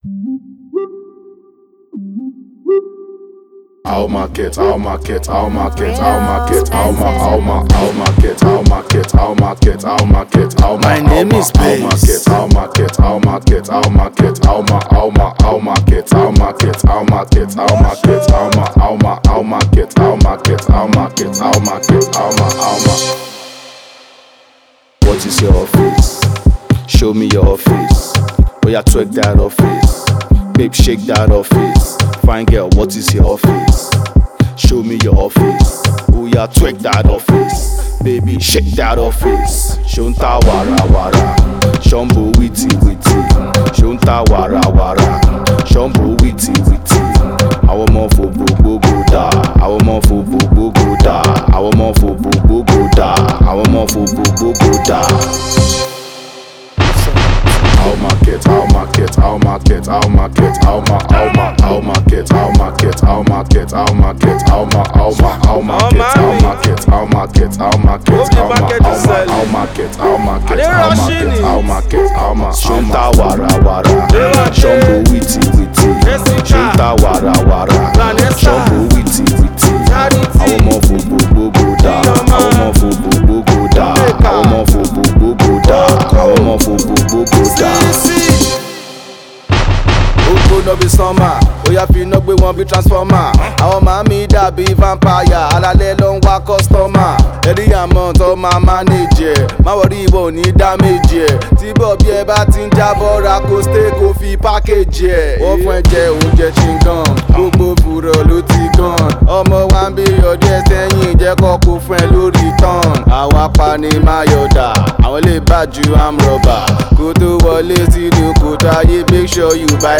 Alternative Rap
alternative Yoruba rapper